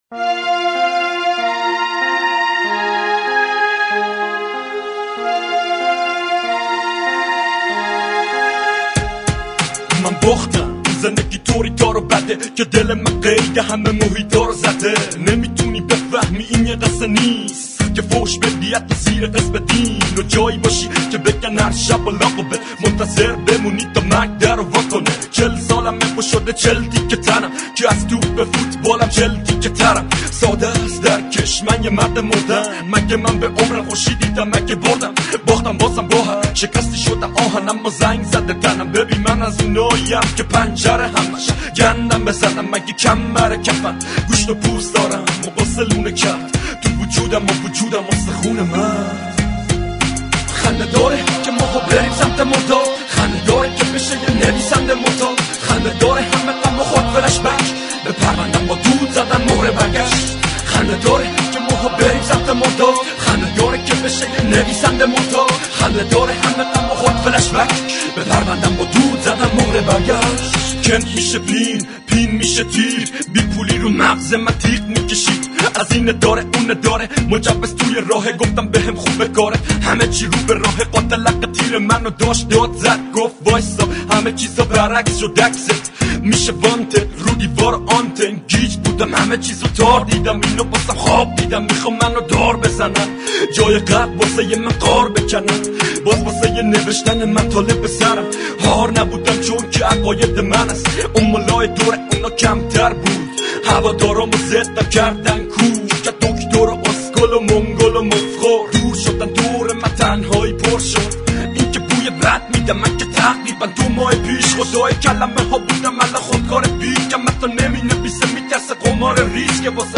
رپ قدیمی